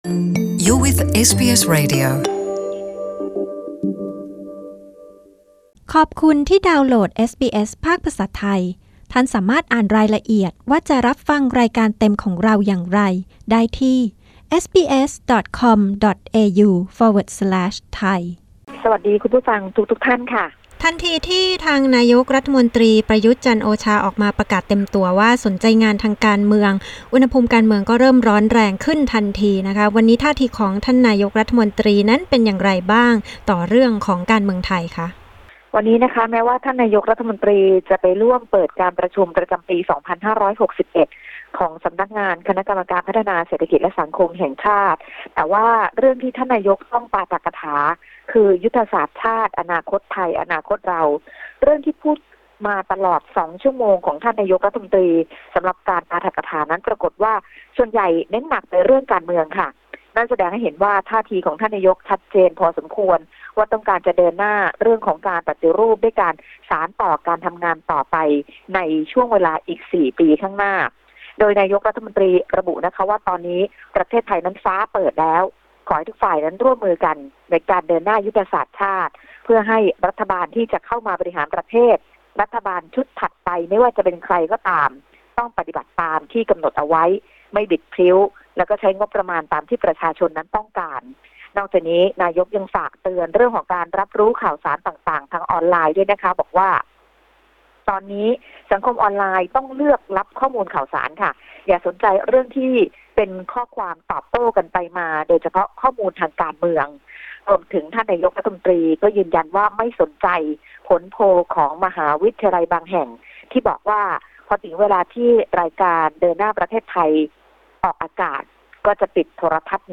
Thai phone-in news SEP 27, 2018